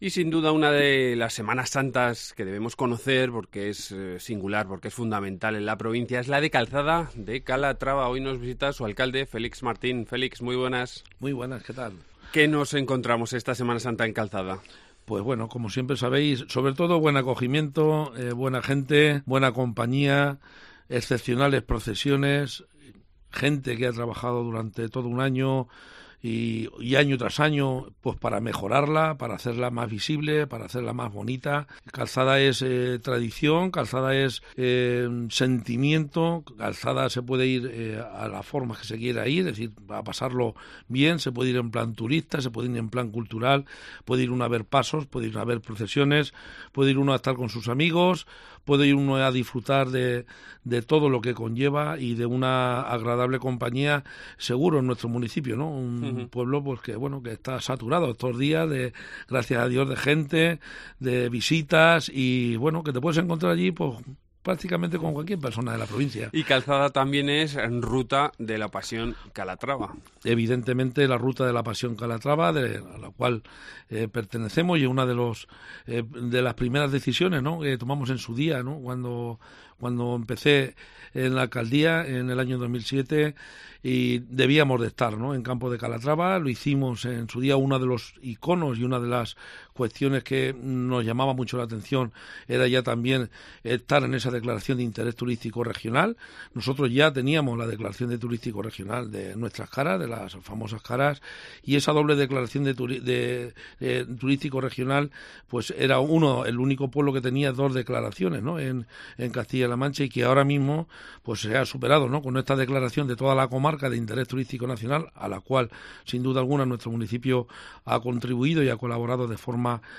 Lunes Santo, y hoy nos vamos hasta Calzada, o mejor dicho, Calzada se viene a la Cope, está con nosotros su alcalde Félix Martín y con él conocemos un poco más su semana grande y también nos detenemos en el famoso juego de Las Caras.